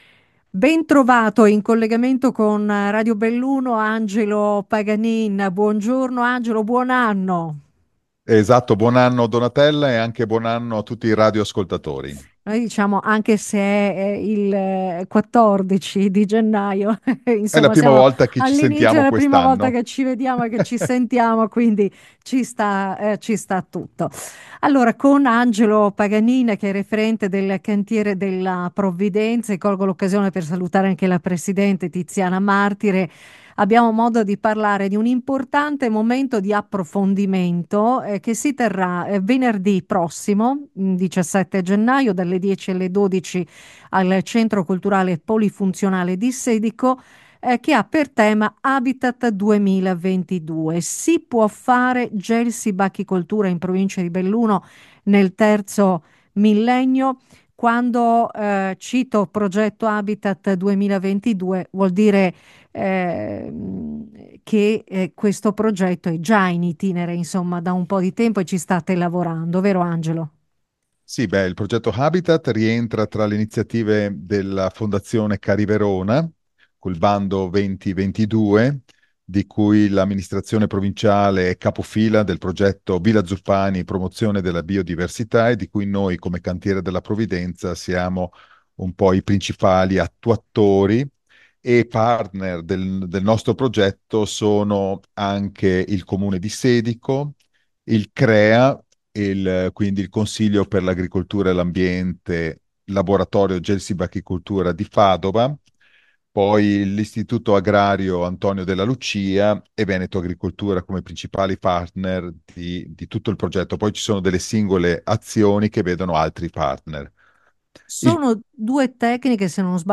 Radio Belluno - Intervista